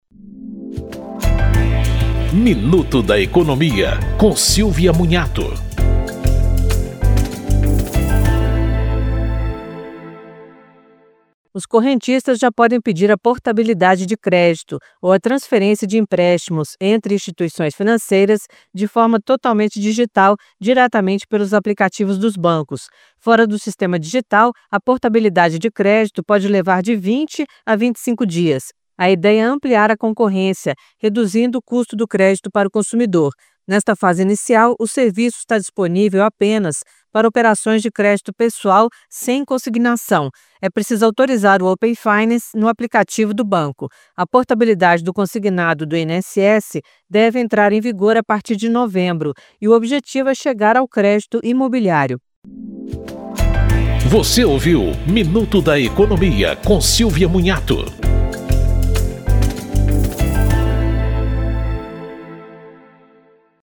Apresentação